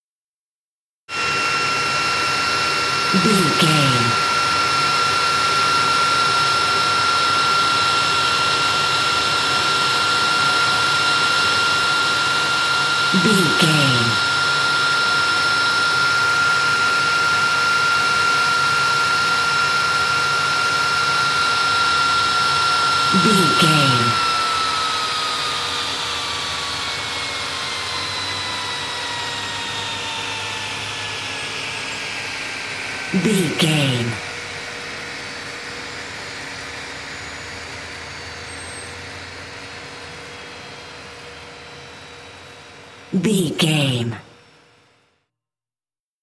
Airplane turbine off
Sound Effects